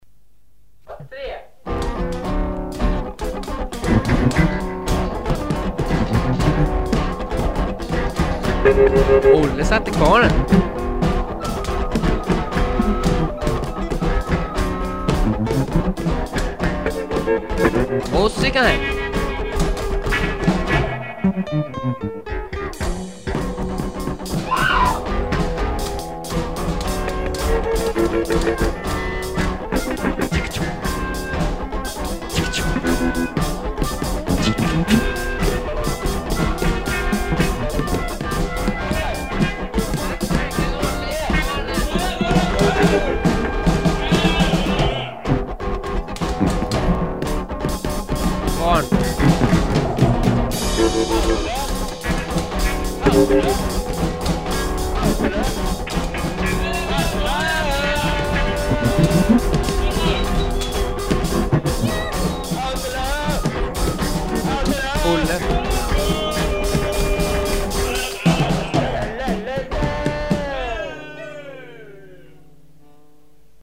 Percussion instruments
Guitar, Voice, Various instruments